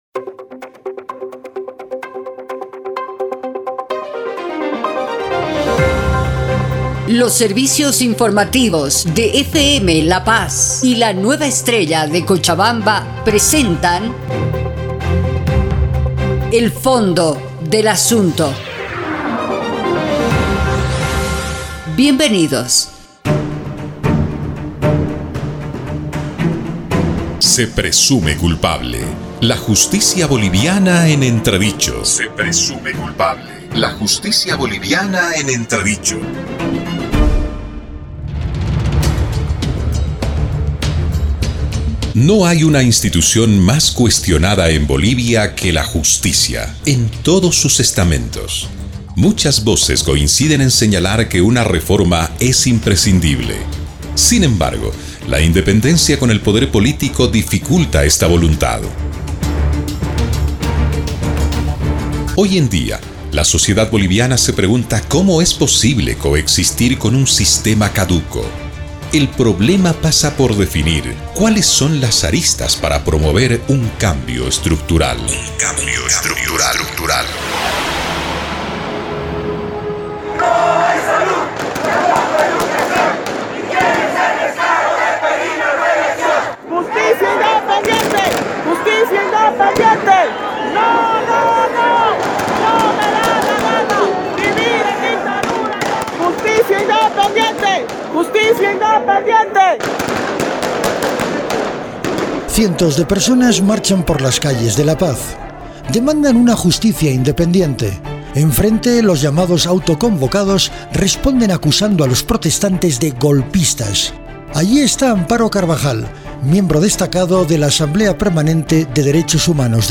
Un programa de reportajes